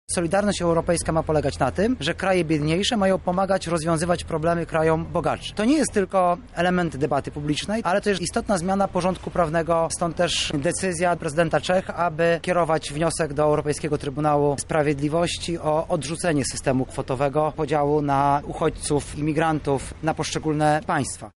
– mówi kandydujący z listy PiS, Artur Soboń